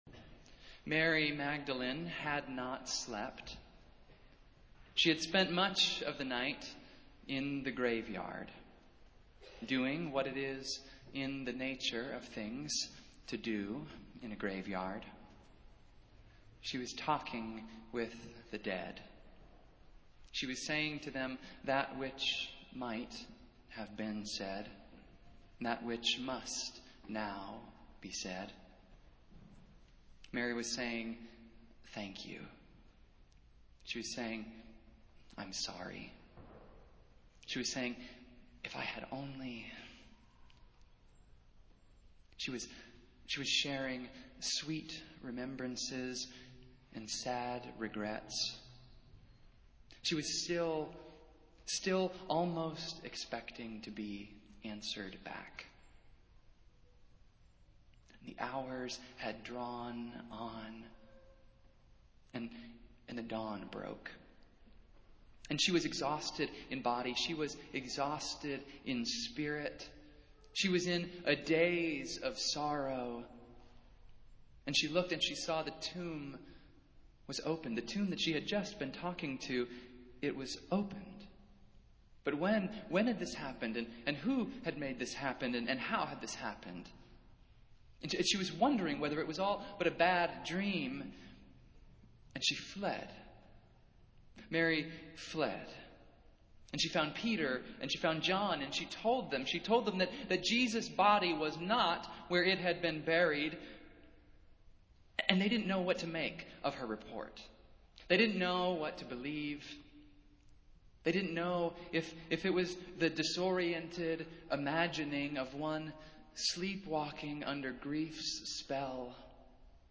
Festival Worship - Second Sunday of Easter